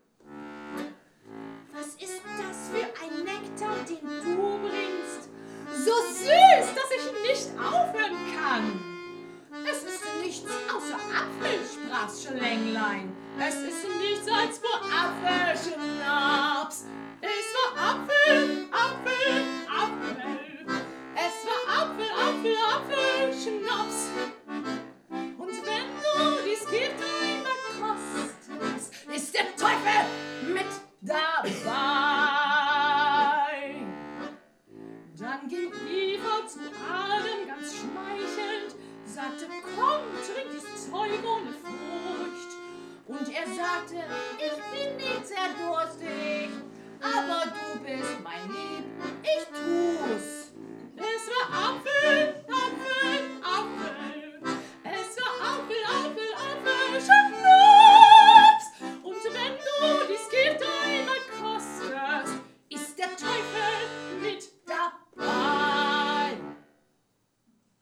Ein Komponist und eine Stimme und ein Akkordeon.
Gesang
Akkordeon
Live